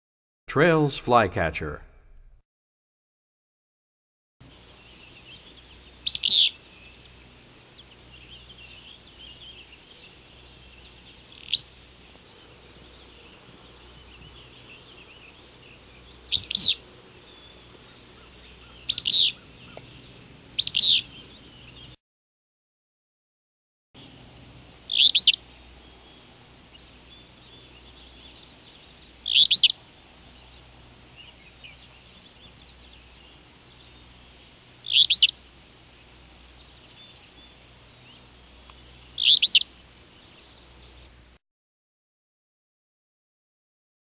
90 Traill's Flycatcher.mp3